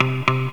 RIFFGTR 07-L.wav